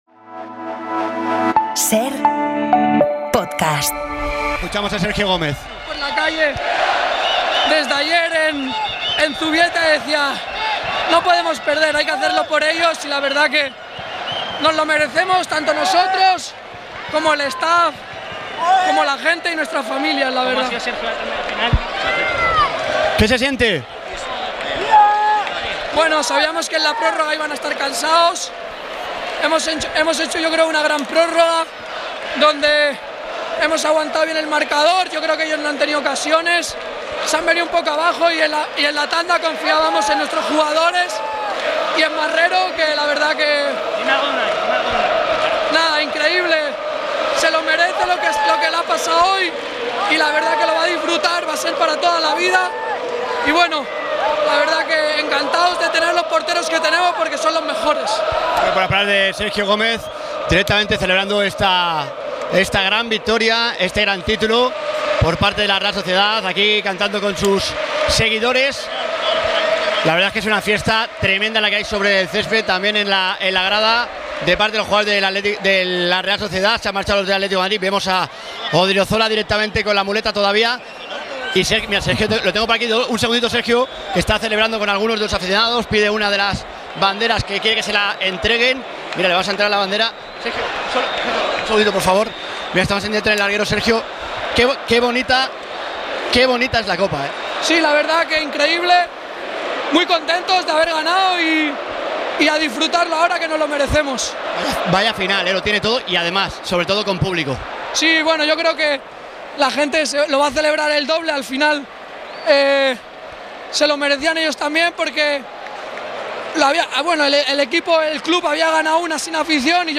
Los jugadores de la Real Sociedad celebran el título de Copa en Carrusel: «Yo me puedo morir ya»